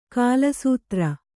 ♪ kāla sūtra